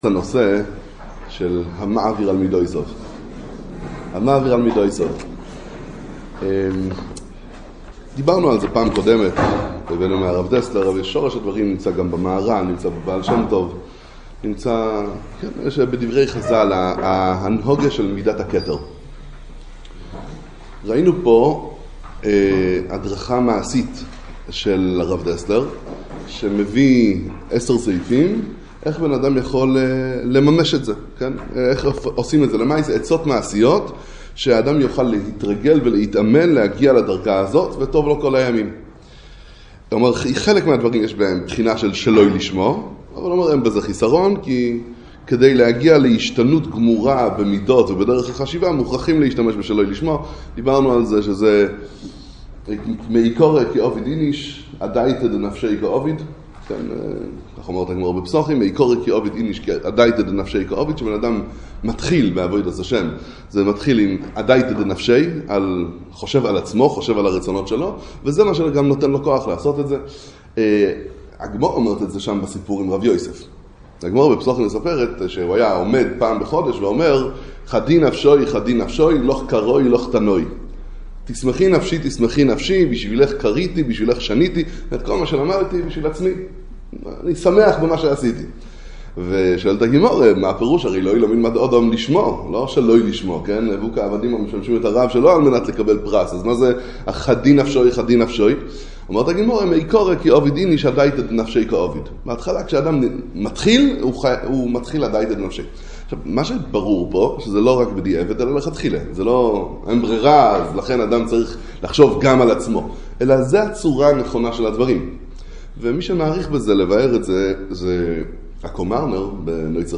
הדרך להגיע לגדלות - עצות מעשיות לזכות להעביר על מידותיו - הרצאה שמינית בכולל לערנען רמת בית שמש